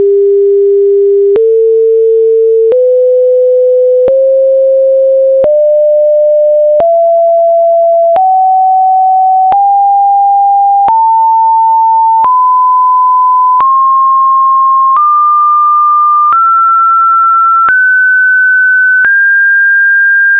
今度は2オ
＜振動数が等比数列のドレミファ＞　　　　＜振動数が等差数列のドレミファ＞
等比数列のほうは、1オクターブ目と、2オクターブ目は「相似」な音階である印象を持ったと思